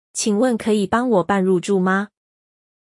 Qǐngwèn kěyǐ bāng wǒ bàn rùzhù ma?